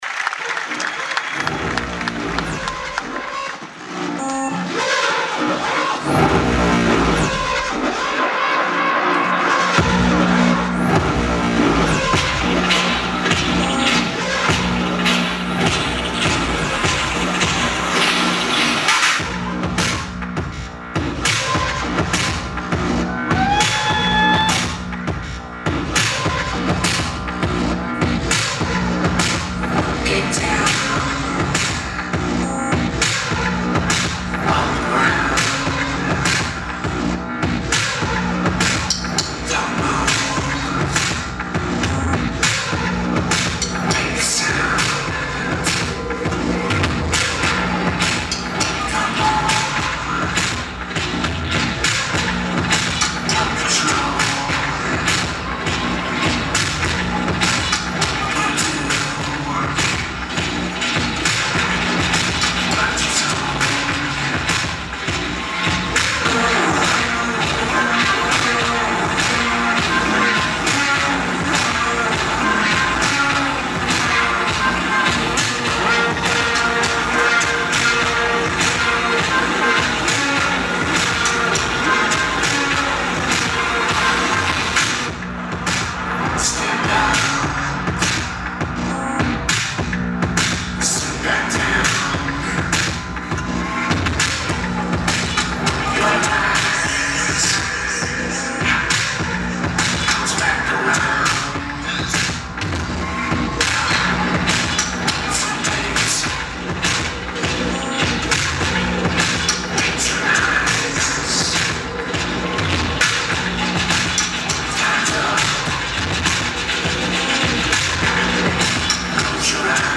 The Apollo Theater
Lineage: Audio - AUD (Canon PowerShow SX260 HX Camera)